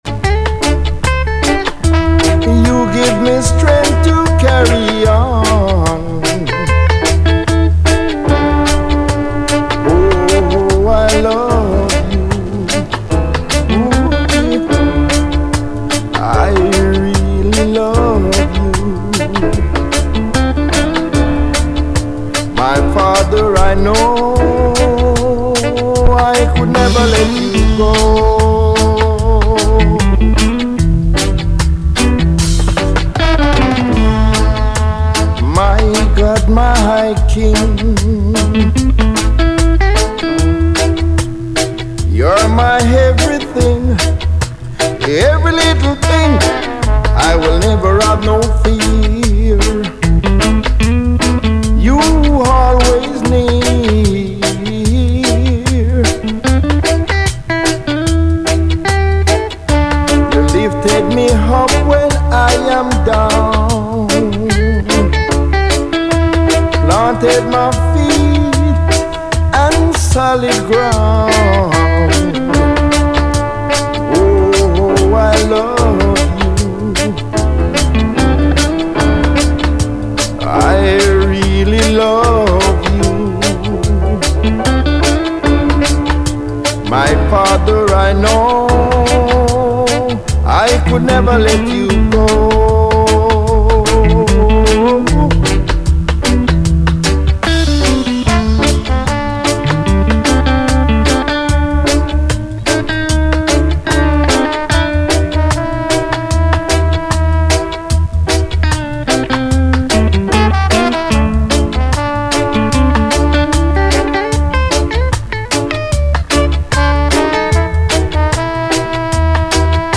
Piano Vibration